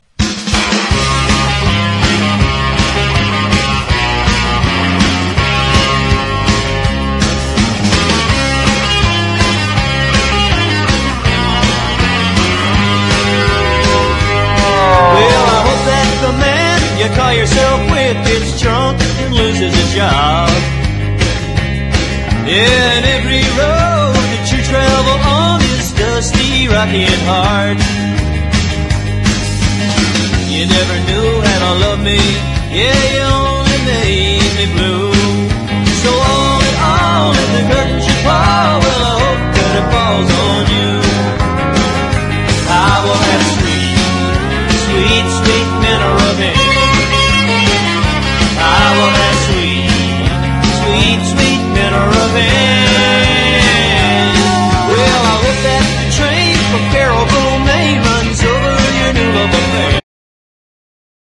SWAMP ROCK
カリビアン・ムードのトロピカル・フォーク
都会的なナイス・カントリー・ロック